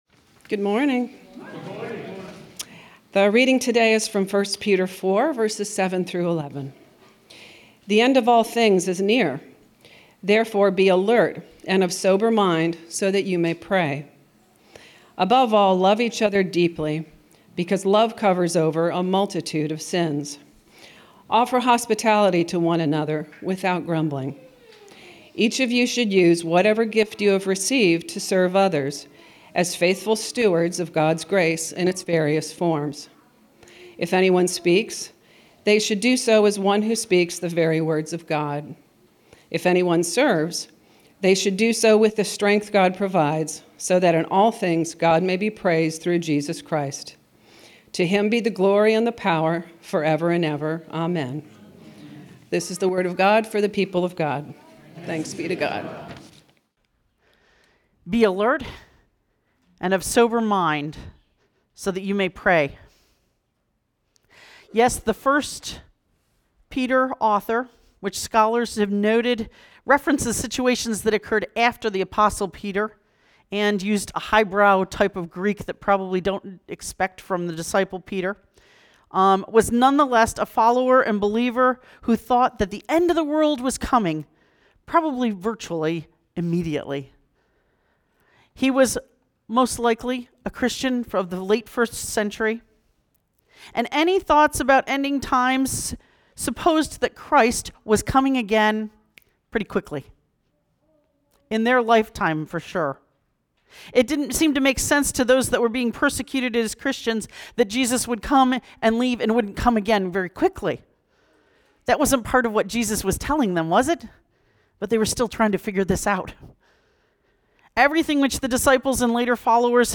October 19, 2025 Sermon Audio